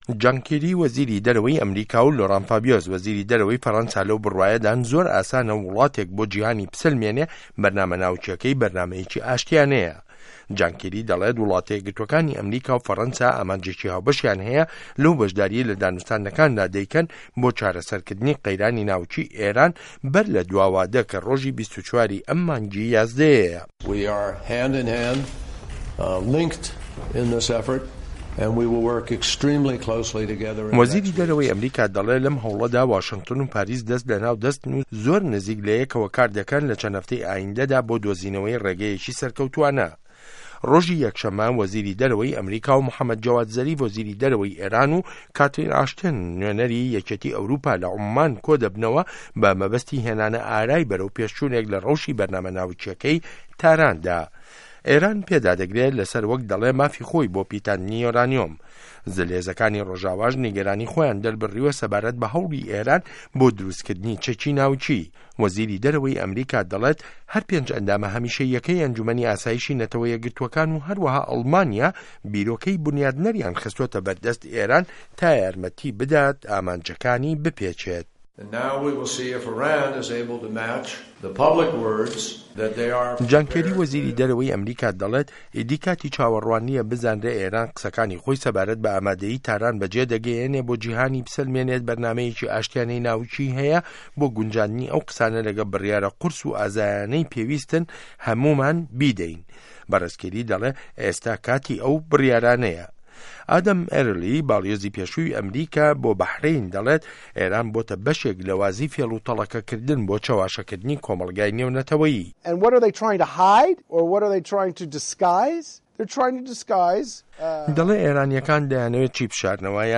ڕاپـۆرتی ئێران